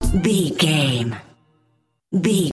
Aeolian/Minor
synthesiser
drum machine
aggressive
hard hitting